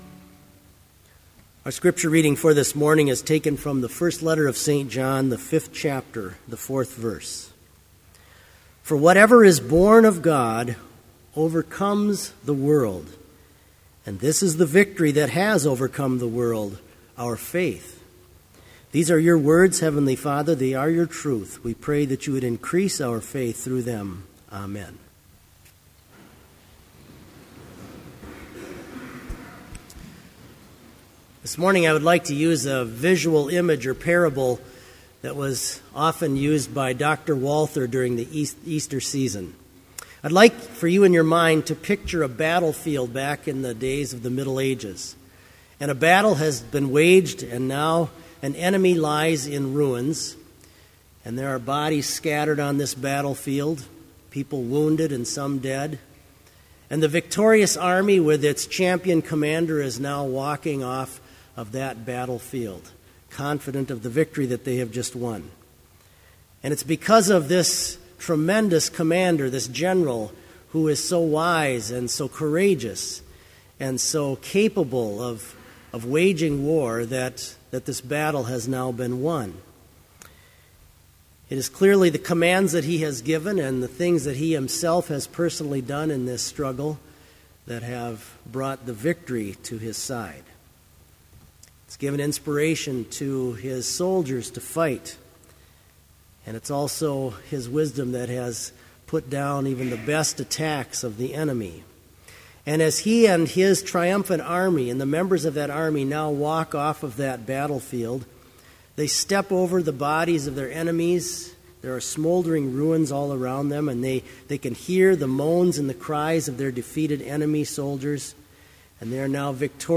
Complete Service
Order of Service
• Hymn 351, vv. 1-5, I Know that My Redeemer Lives
• Homily
This Chapel Service was held in Trinity Chapel at Bethany Lutheran College on Friday, April 12, 2013, at 10 a.m. Page and hymn numbers are from the Evangelical Lutheran Hymnary.